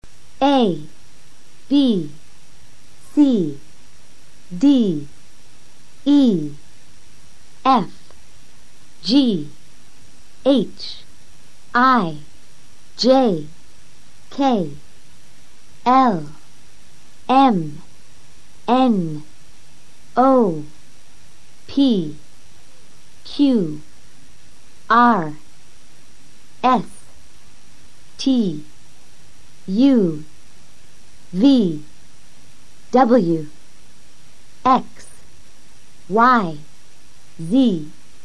Escucha el abecedario y luego intenta repetirlo simultáneamente con el profesor.